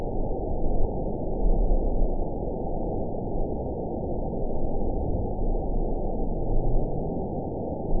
event 922688 date 03/10/25 time 08:02:32 GMT (3 months, 1 week ago) score 6.96 location TSS-AB10 detected by nrw target species NRW annotations +NRW Spectrogram: Frequency (kHz) vs. Time (s) audio not available .wav